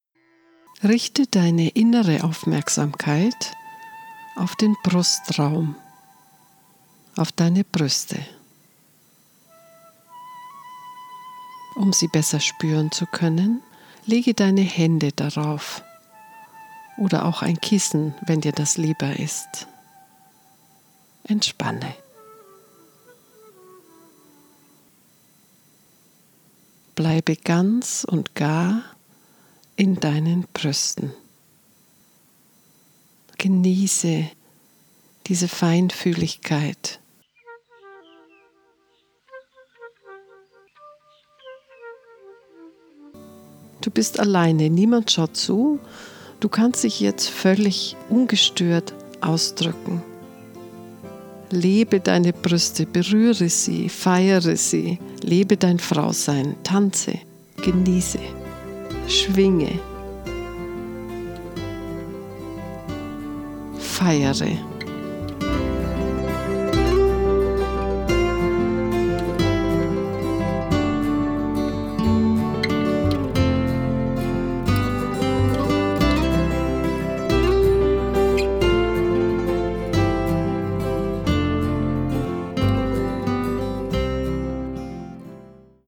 Ein kurzer Zusammenschnitt aus der Anleitung (1:26)
Geführte Meditation nur für Frauen